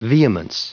Prononciation du mot vehemence en anglais (fichier audio)
Prononciation du mot : vehemence